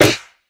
Snare (Baby).wav